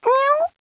fox1.wav